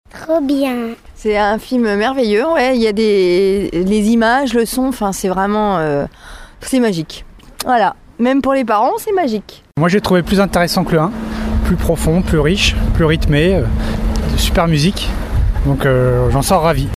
Et certains piaffaient d’impatience avant de découvrir ce 2e opus :